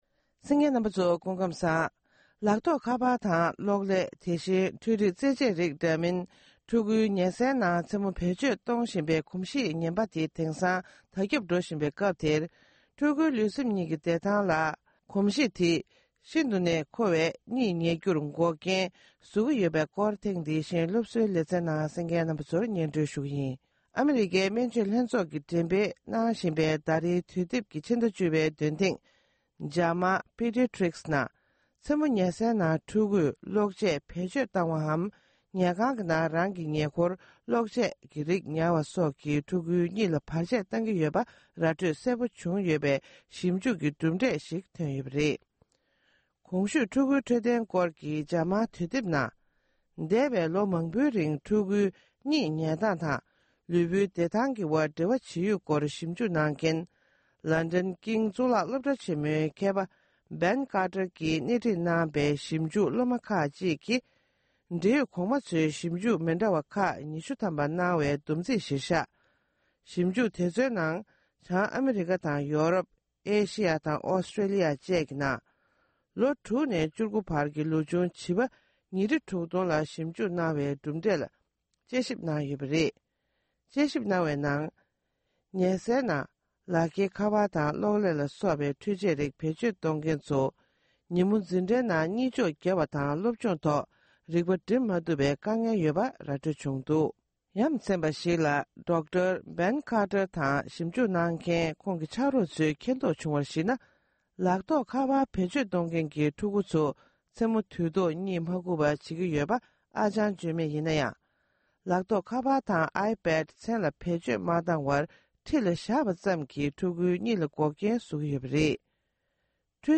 འཕྲུལ་ཆས་ཀྱིས་ཕྲུ་གུའི་གཉིད་ཉལ་རྒྱུར་འགོག་རྐྱེན་བཟོ་སྟངས། སྒྲ་ལྡན་གསར་འགྱུར།